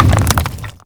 stonedeath.wav